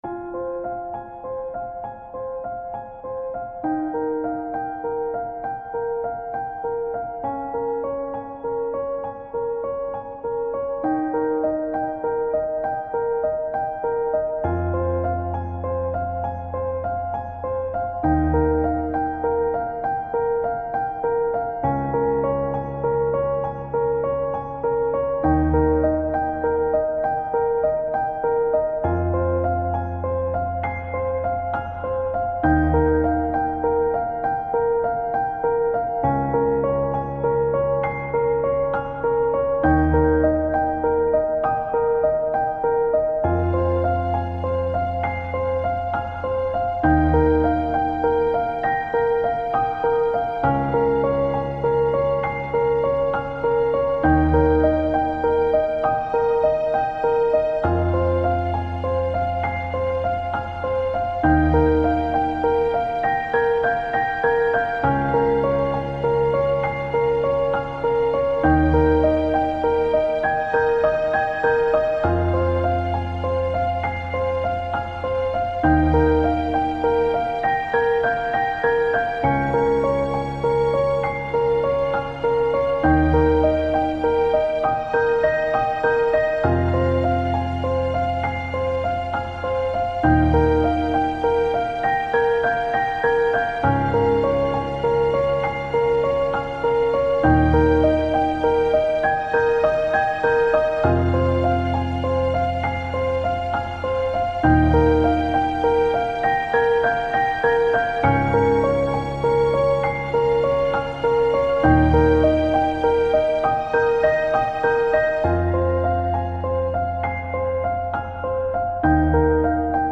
Грустные клавиши тревожной музыки